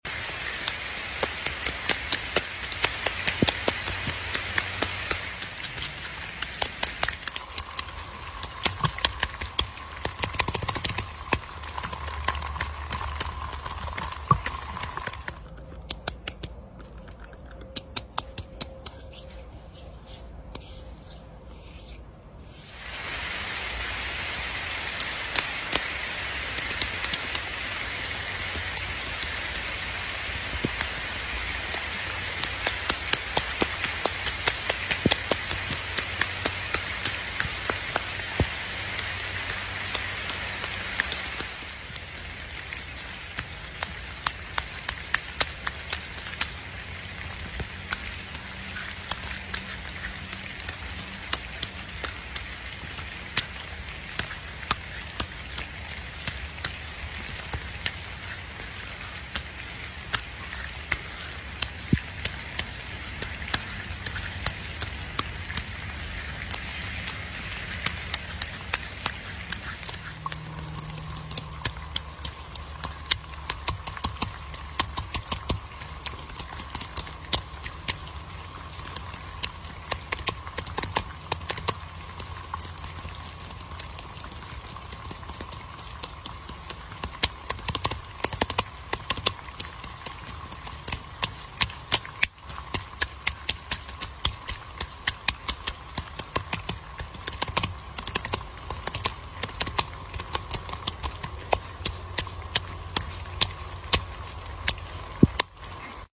ASMR Giving A Tree A Sound Effects Free Download